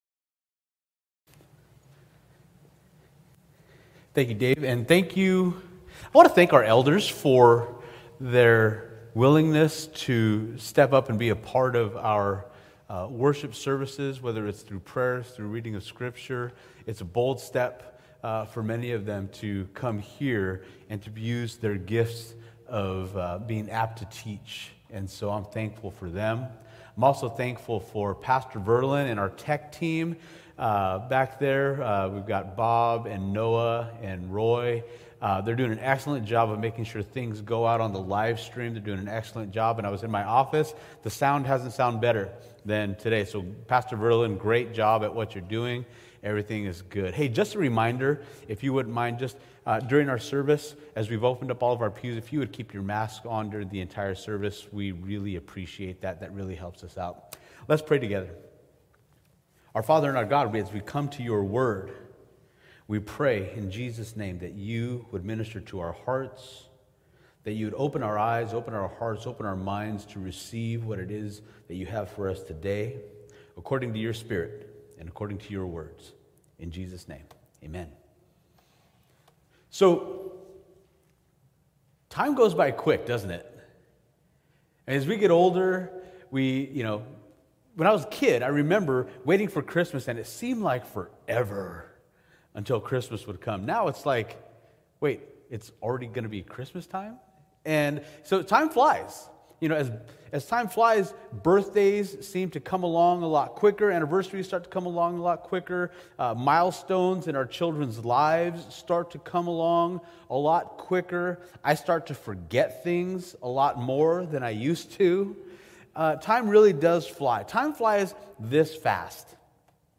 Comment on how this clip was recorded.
Sunday-Service-March-7-A-Superior-Sacrifice.mp3